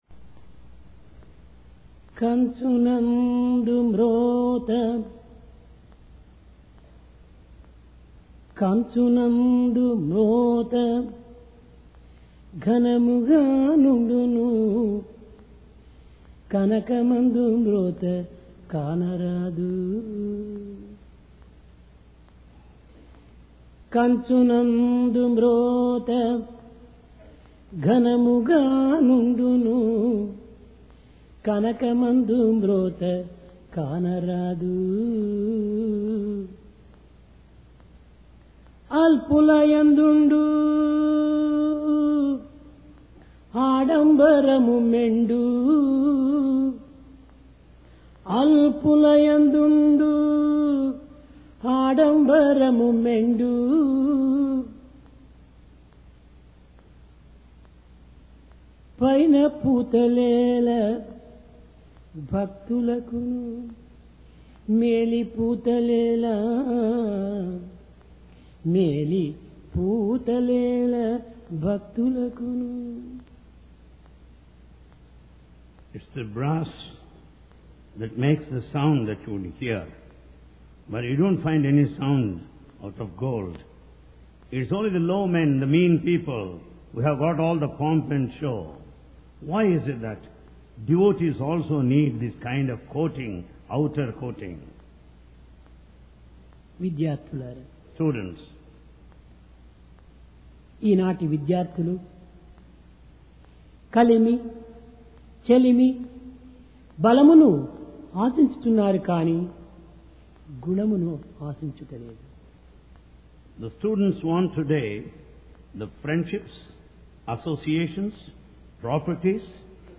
PRASHANTI VAHINI - DIVINE DISCOURSE 10 JULY, 1996
Sai Darshan Home Date: 10 Jul 1996 Occasion: Divine Discourse Place: Prashanti Nilayam Be Like Gold Not Brass It is the brass that makes sound.